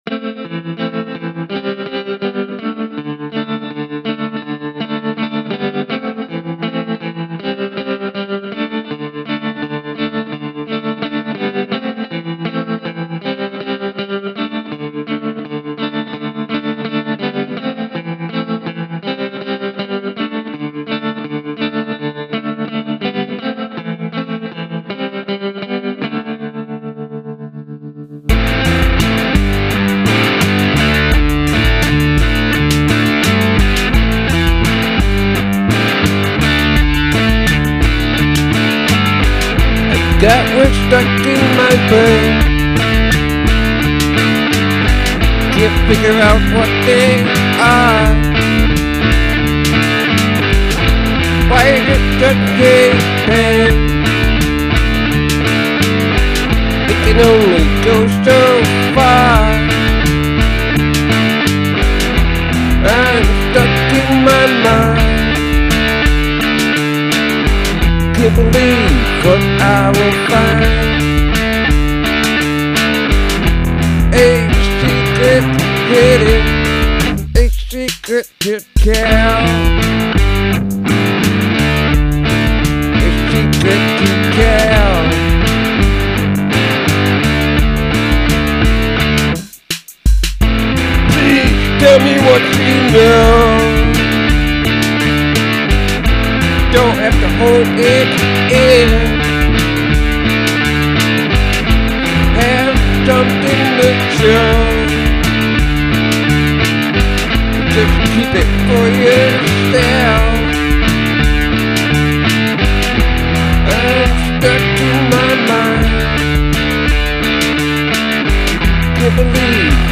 New original song (full band): “A Secret Hidden”
The more I make this music in Garageband, the more it makes me want to form a real band.